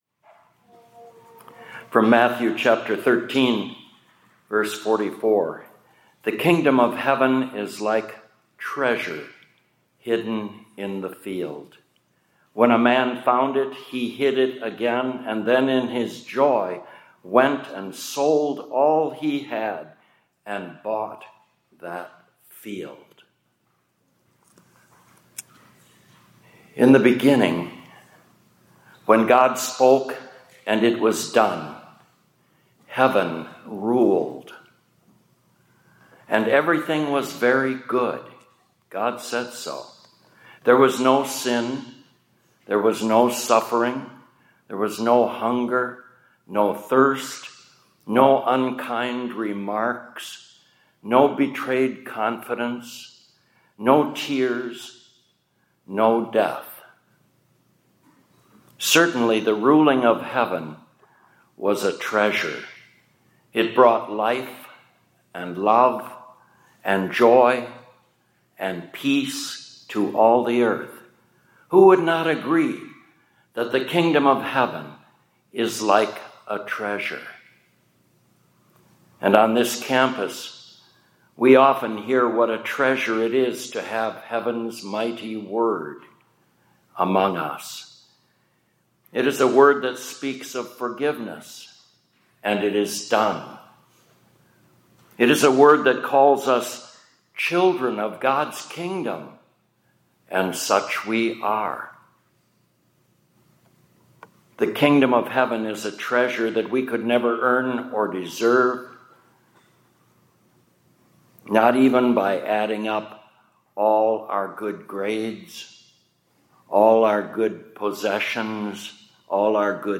2025-10-15 ILC Chapel — We’re Not Crackers and Cheese…